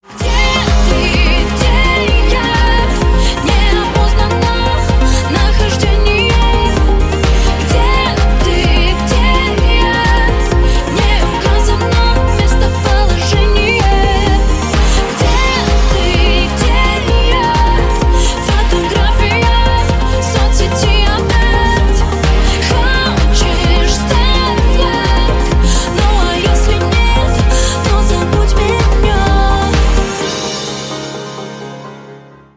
попса